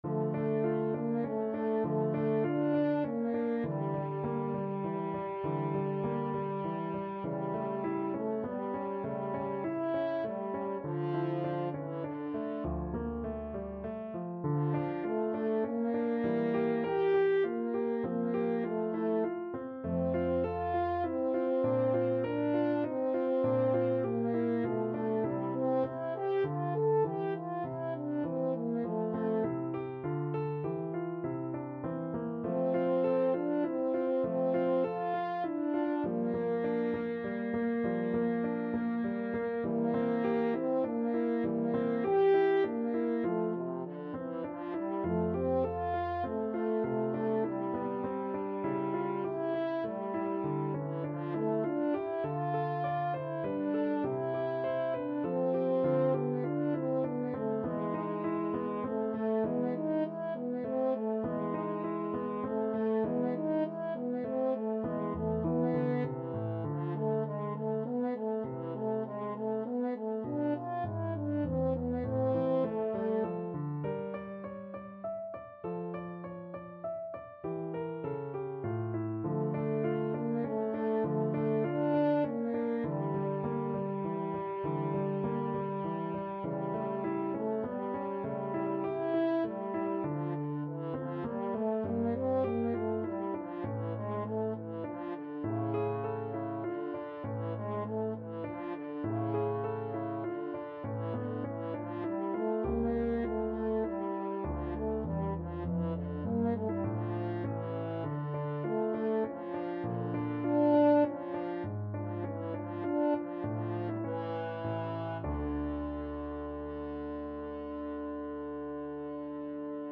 6/8 (View more 6/8 Music)
Siciliano =100
Classical (View more Classical French Horn Music)